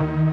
Freq-lead19.ogg